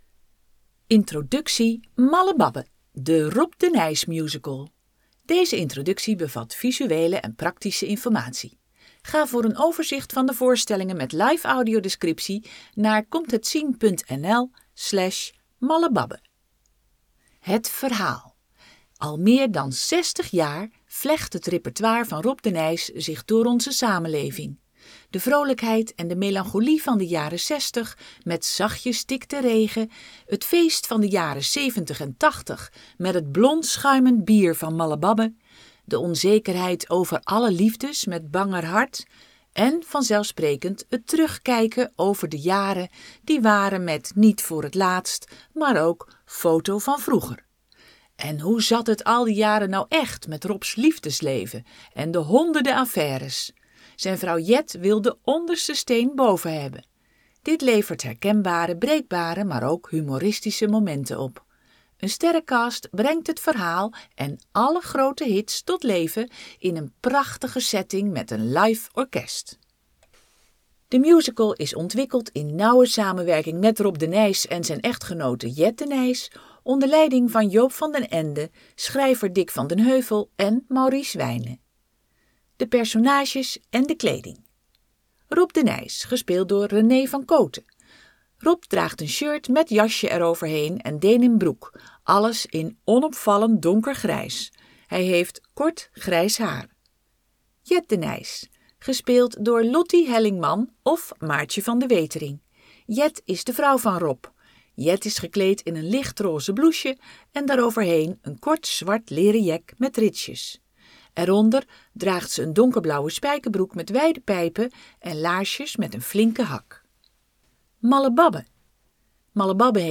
De introductie is hieronder beschikbaar als Word bestand, PDF, ingesproken audio en als website tekst.